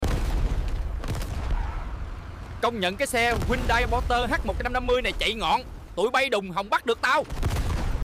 Hyundai Porter H150 lăn bánh sound effects free download